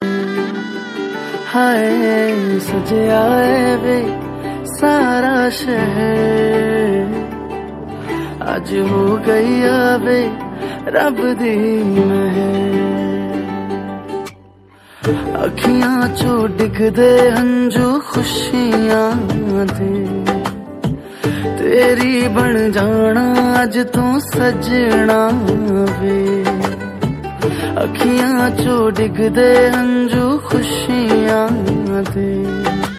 Category Punjabi